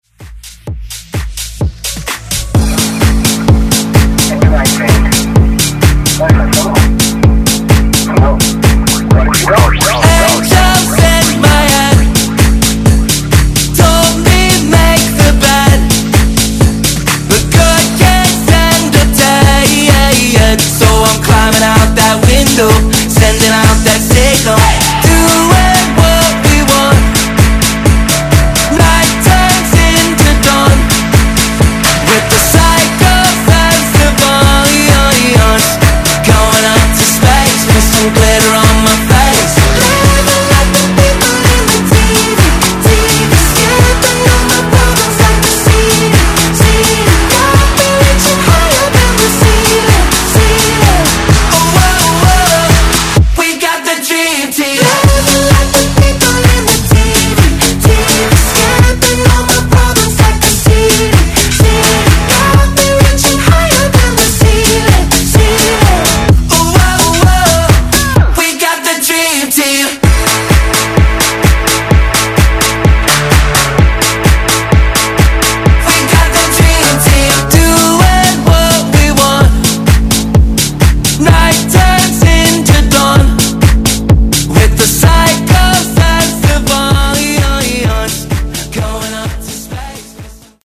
Genre: DANCE
Clean BPM: 125 Time